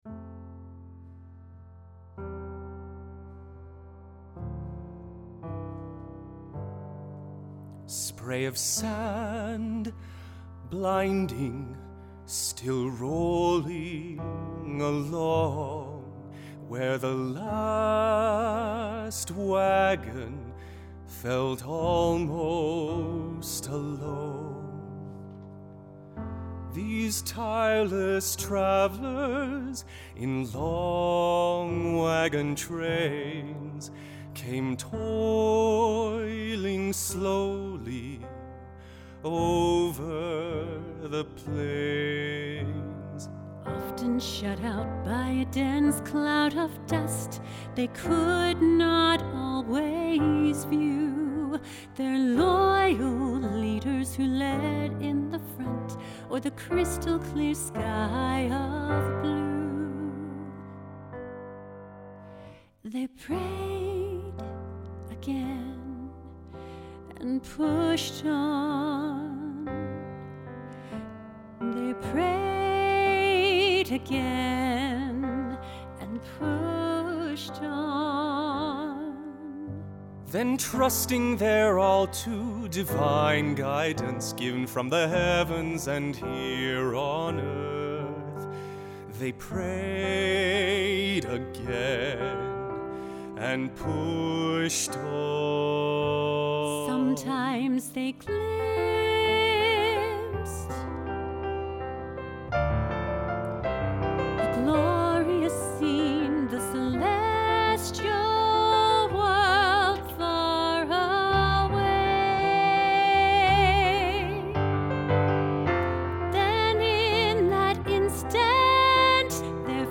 Voicing/Instrumentation: Duet